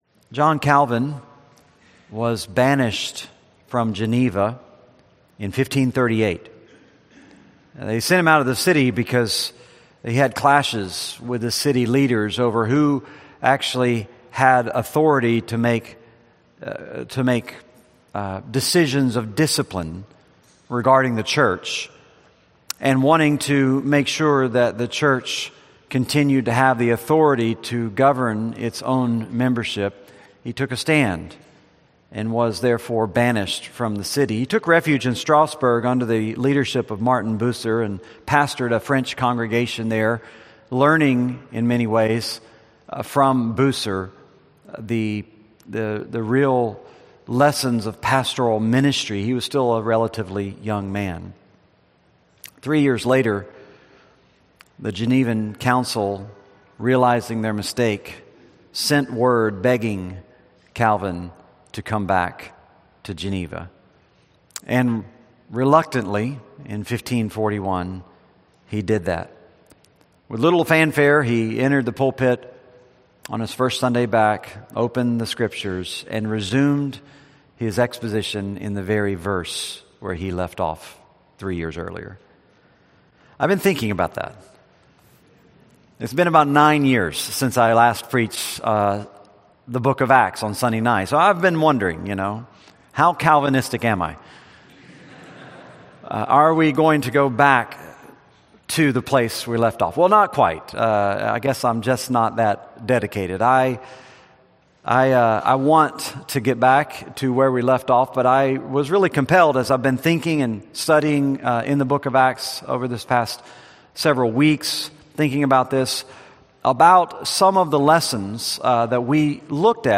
Series: Benediction Evening Service, Sunday Sermons